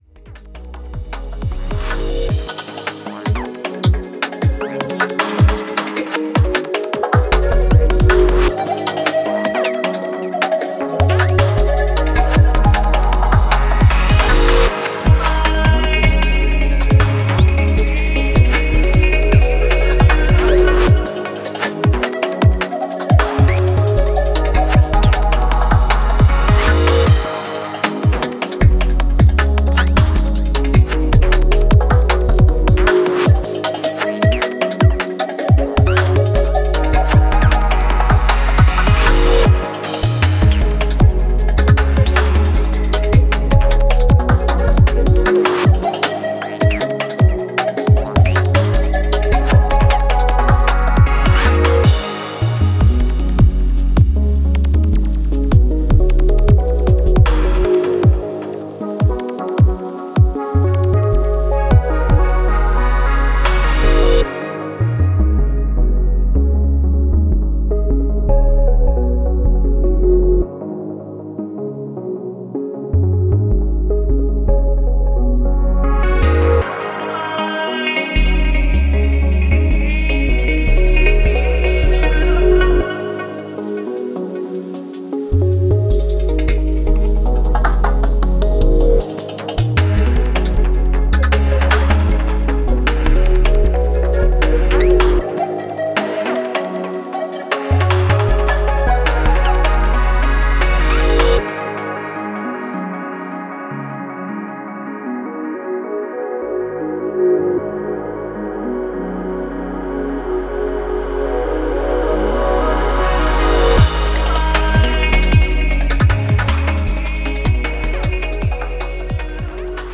Bass , Progressive Trance , Techno